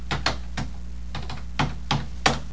00248_Sound_Typing.mp3